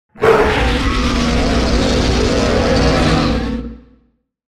Gemafreie Sounds: Kreaturen
mf_SE-9033-long_monster_growl.mp3